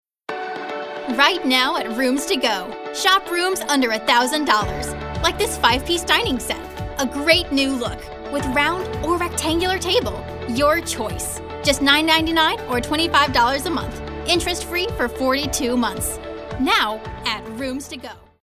animated, announcer, confident, high-energy, retail, upbeat, young, young adult